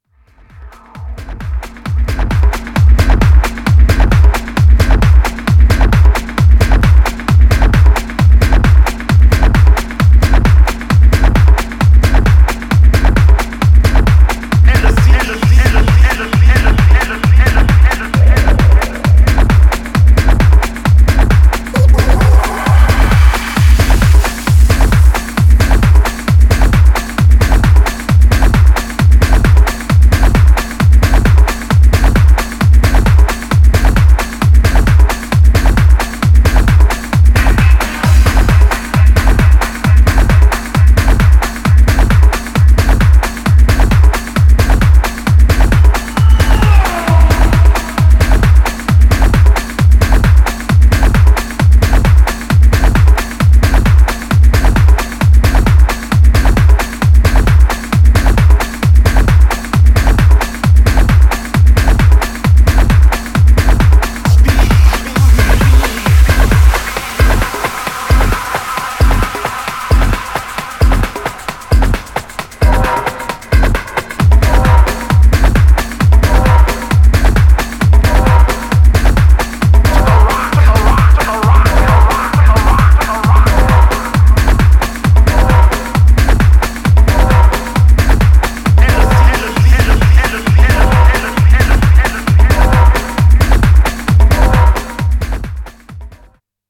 Styl: Progressive, House, Techno, Breaks/Breakbeat